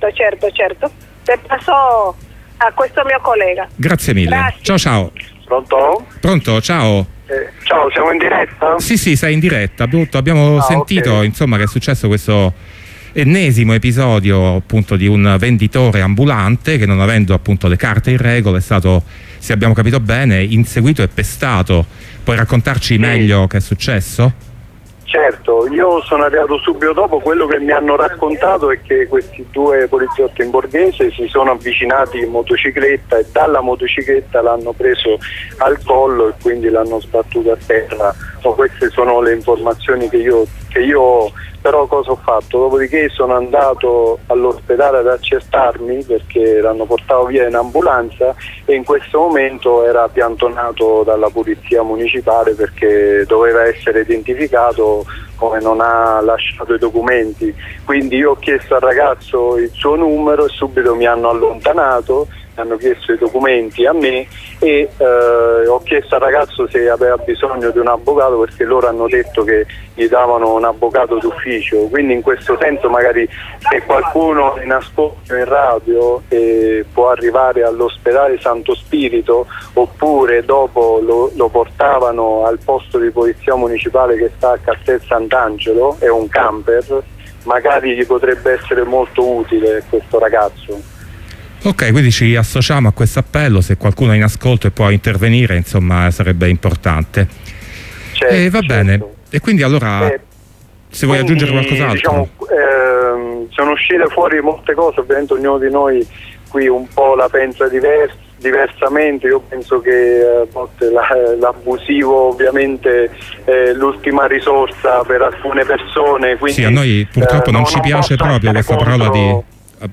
Oggi un ambulante abusivo e' stato picchiato con violenza da forze dell'ordine in borghese. Nel primo audio (di 5 minuti) una persona informata sui fatti ci racconta l'episodio e il clima a contorno.